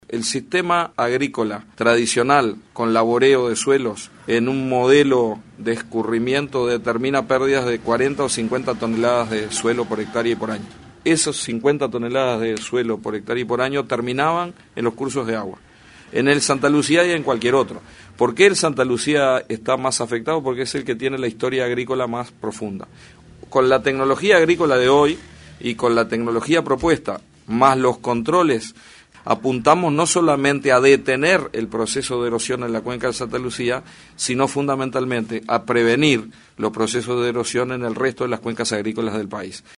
En el marco de la conferencia "Inocuidad de los alimentos, garantías para tu salud";, Aguerre dijo que este sistema quedará operativo a partir del segundo semestre del año.